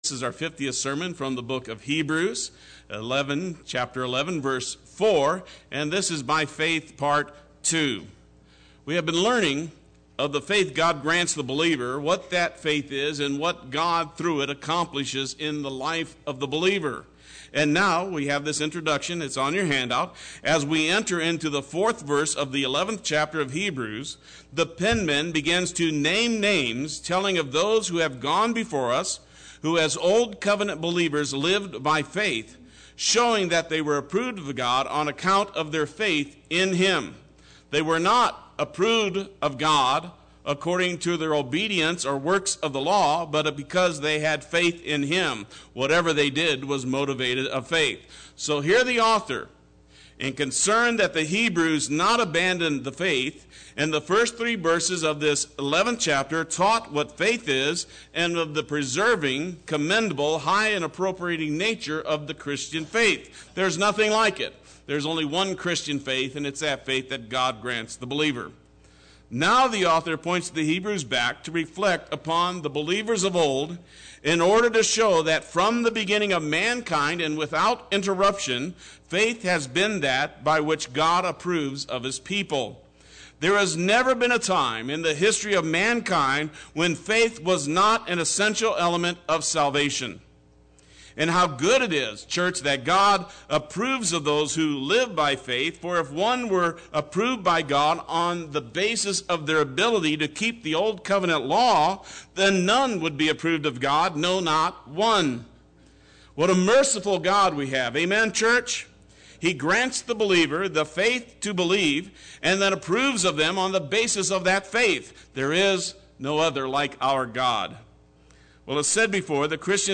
Play Sermon Get HCF Teaching Automatically.
Part 2 Sunday Worship